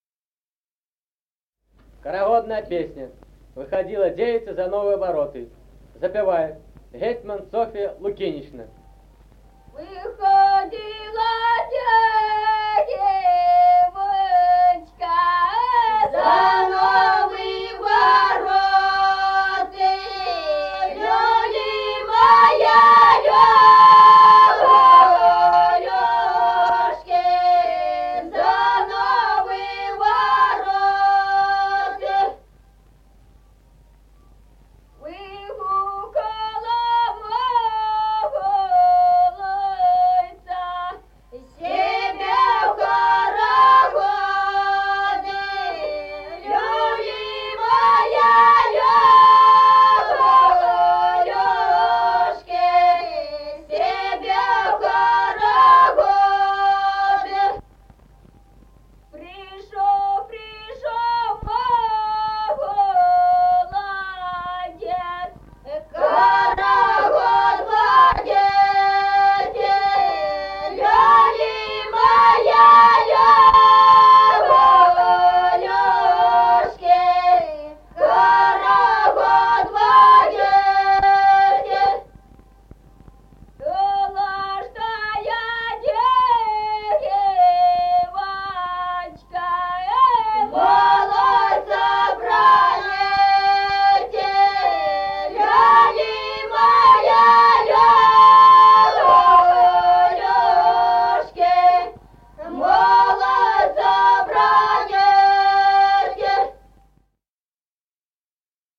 Музыкальный фольклор села Мишковка «Выходила девочка», хороводная.